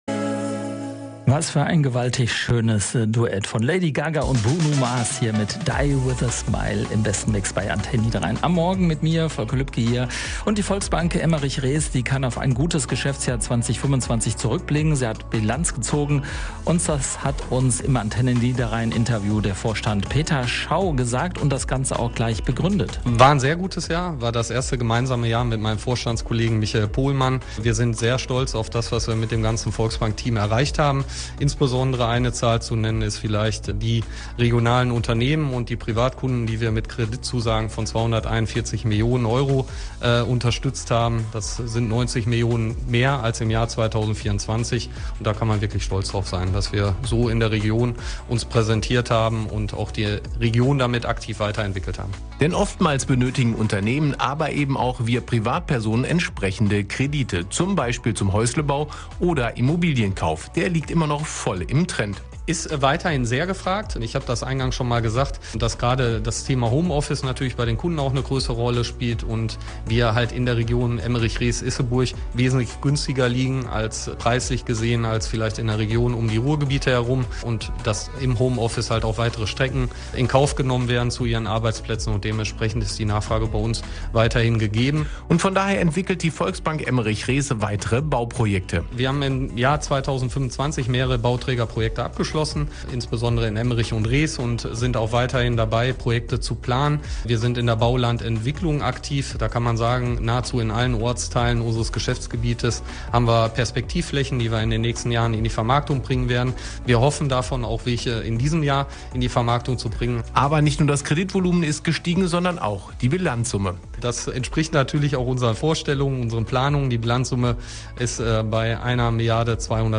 AN-Interview